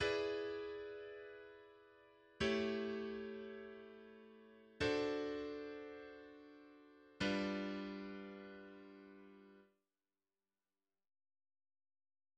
Регтайм-прогрессия (E7–A7–D7–G7) часто встречается в бридже джазовых стандартов[1]. Последовательность III7–VI7–II7–V7 (или V7/V/V/V–V7/V/V–V7/V–V7) возвращает к До мажору (I), но сама по себе не определена по тональности.